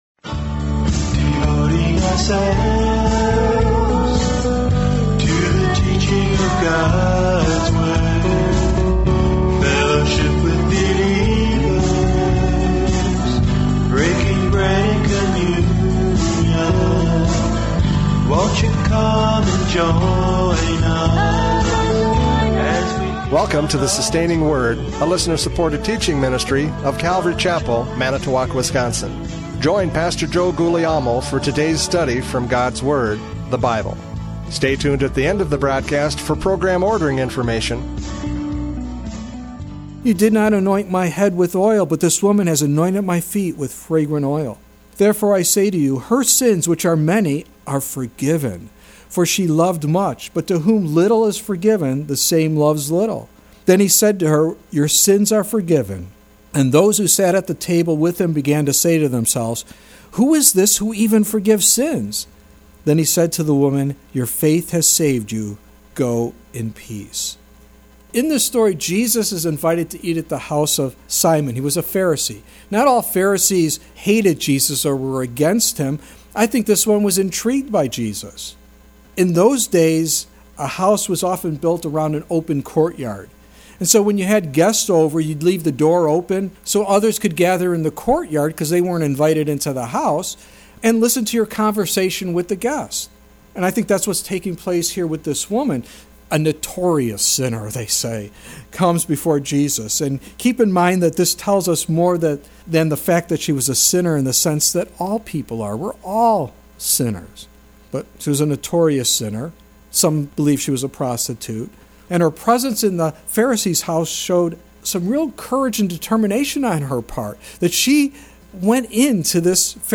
John 5:36 Service Type: Radio Programs « John 5:36 Testimony of Miracles!